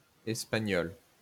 Espagnole sauce (French pronunciation: [ɛspaɲɔl]